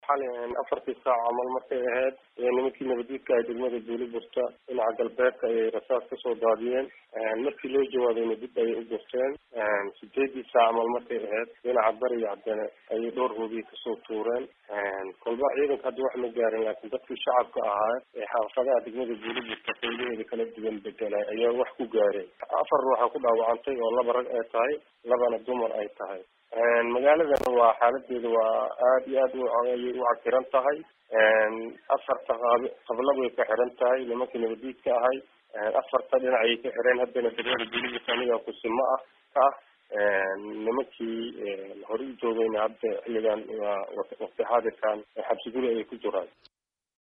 Kusimaha Gudoomiyaha degmada Liibaan Cabdi Burusoow, ayaa wareysi uu siiyay VOA. uga waramay weerardii xalay.